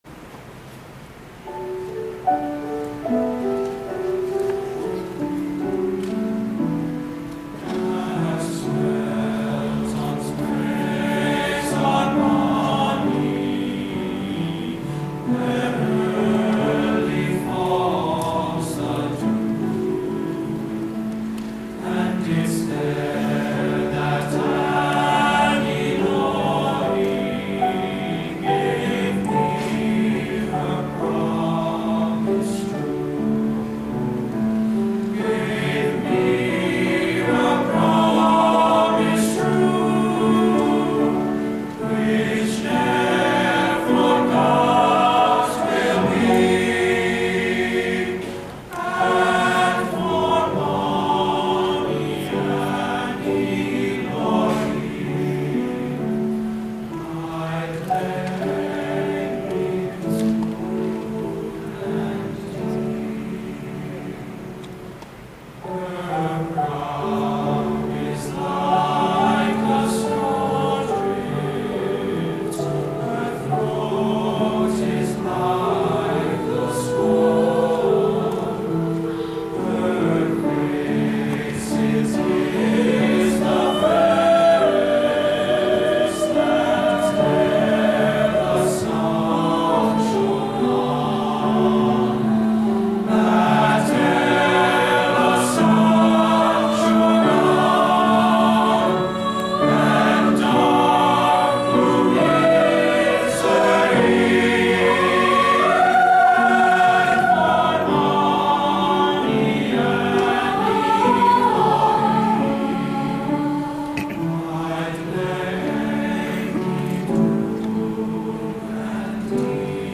Accompaniment:      Piano
Music Category:      Choral